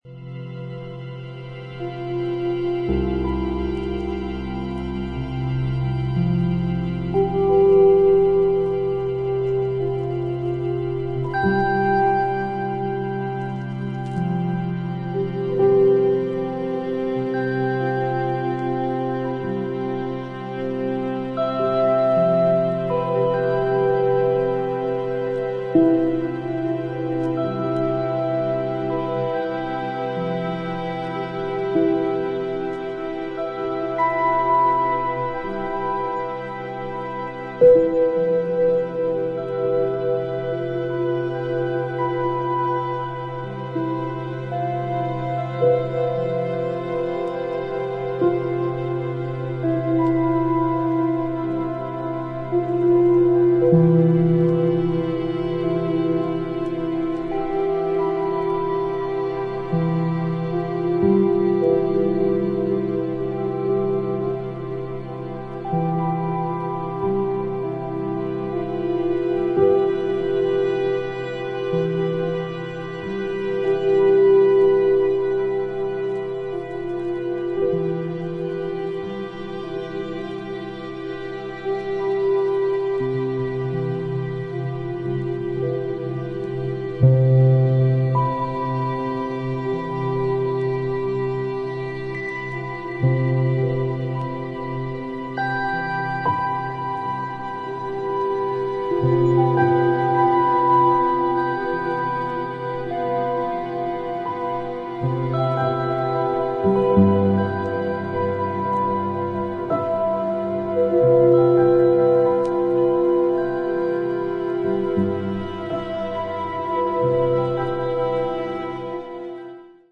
ピアノやヴァイオリン、ヴィオラ、ムーグギター等、穏やかで温かみのある楽器の音色をベースに